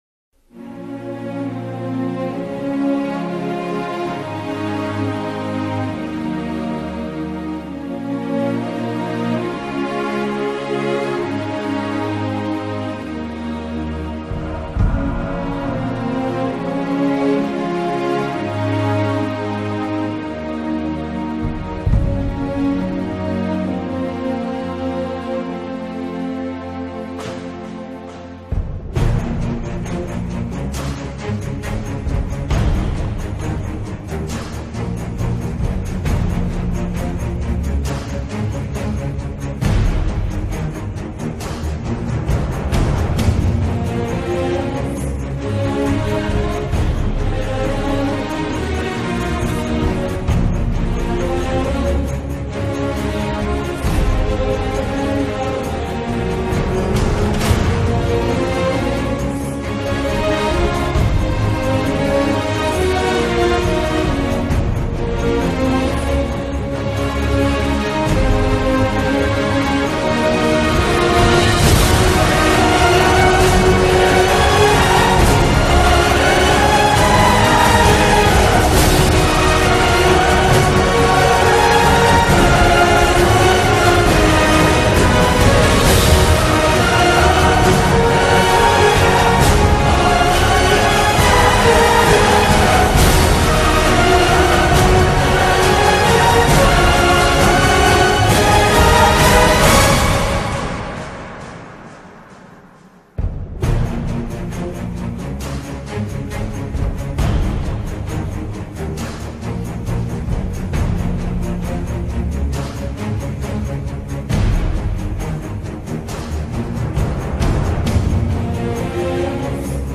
KEzxht445LV_Musica-epica-militares-.m4a